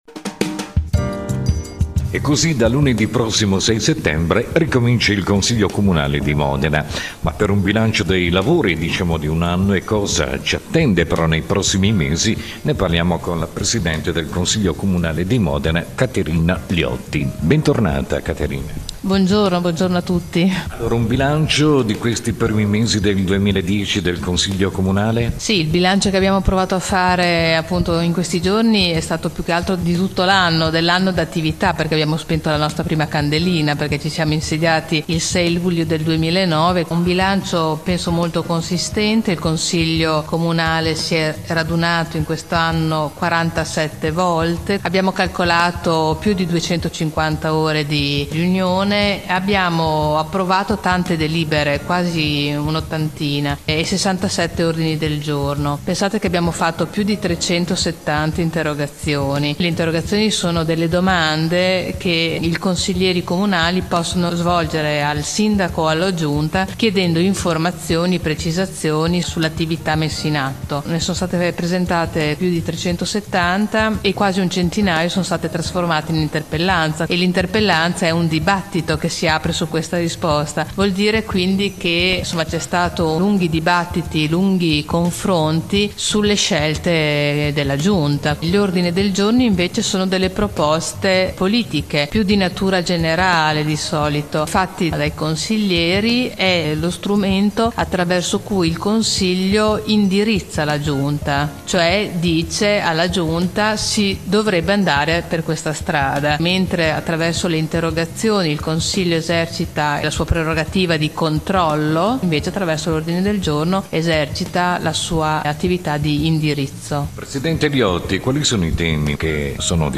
Intervista al Presidente Liotti - 3 settembre — Sito Audio Consiglio Comunale